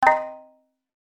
mbira.mp3